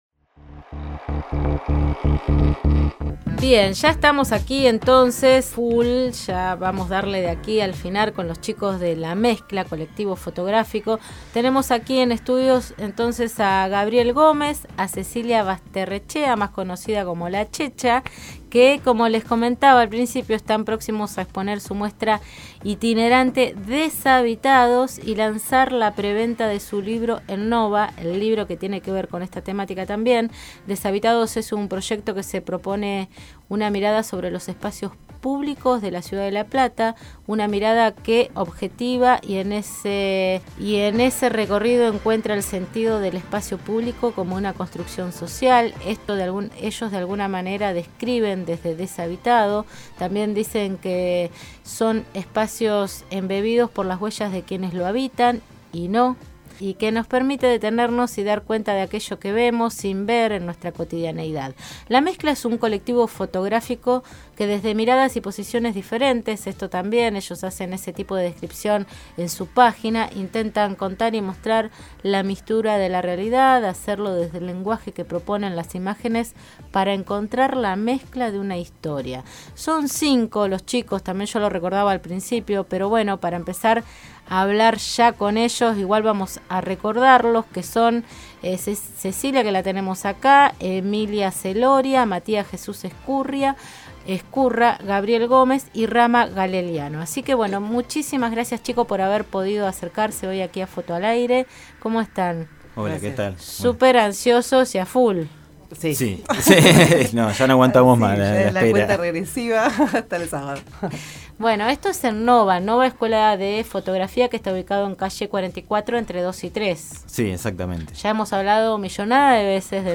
Опубліковано La Mezcla, Colectivo Fotográfico 27 червня 2017 р. Escuchá la entrevista completa: La Mezcla en Foto al Aire- Descargar audio Foto al Aire, todos los miércoles de 20 a 21 hs.